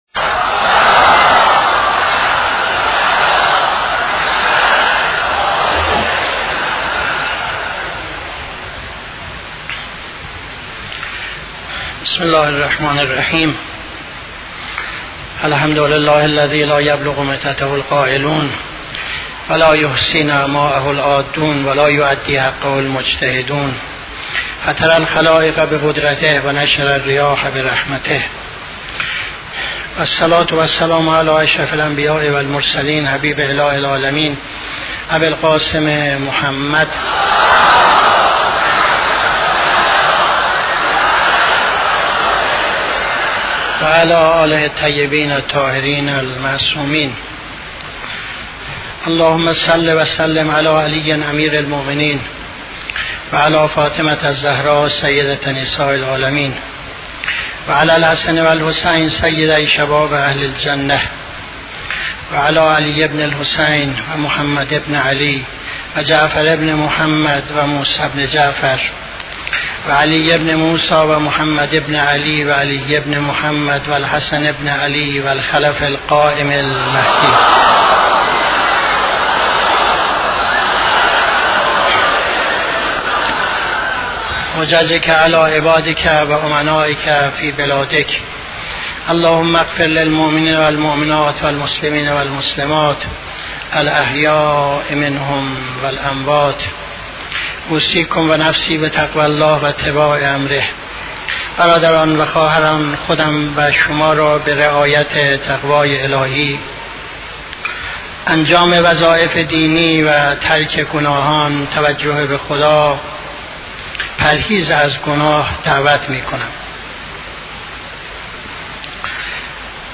خطبه دوم نماز جمعه 30-05-77